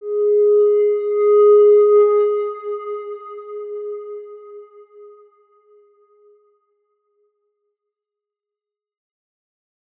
X_Windwistle-G#3-mf.wav